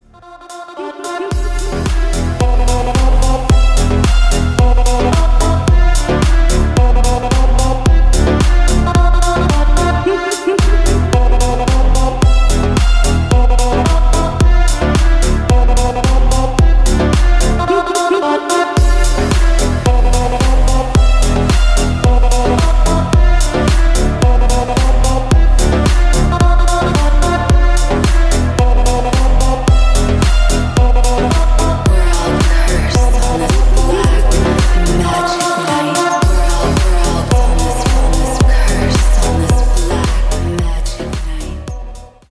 • Качество: 192, Stereo
атмосферные
Electronic
спокойные
Chill House
Стиль: electronica.